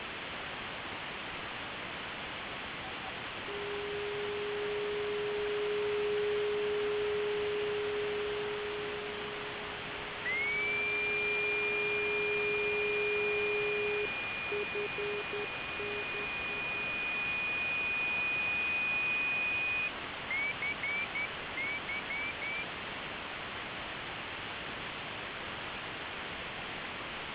Driftnet Beacons f-28059 kHz usb
Начало » Записи » Записи радиопереговоров - корабли и береговые станции
driftnet_beacons_f-28059_khz_usb.mp3